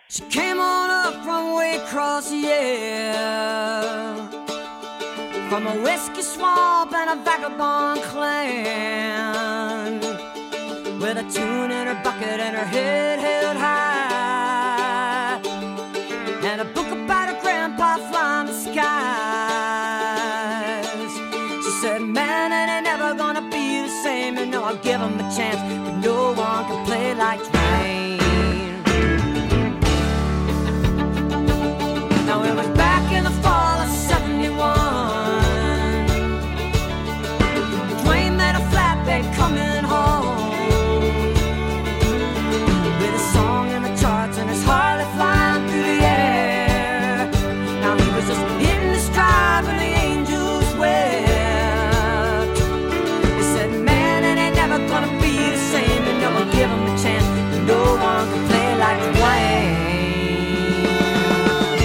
(recorded from webcast)
(album version)